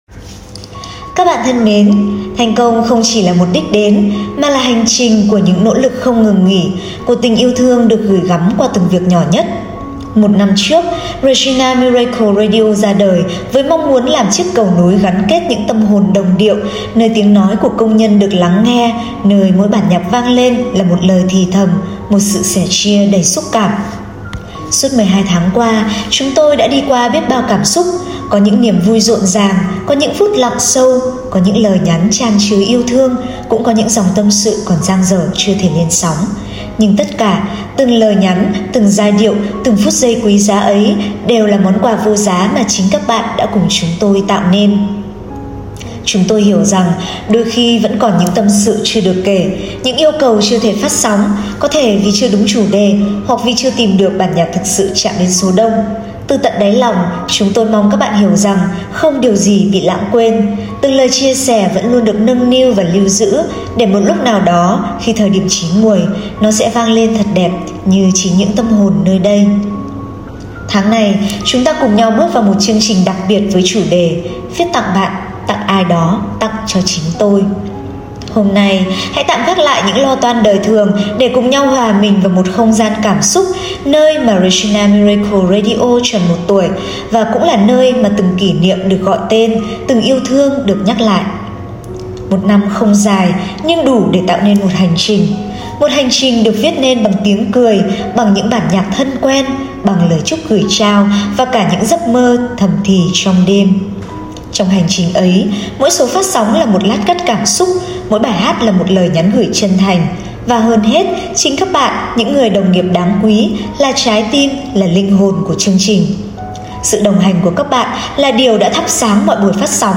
Trong số phát đặc biệt hôm nay, chương trình dành trọn cho cảm xúc: những lời chúc mừng sinh nhật ấm áp, những món quà âm nhạc từ chính các bạn và những câu chuyện nhỏ đời thường nhưng đầy ý nghĩa.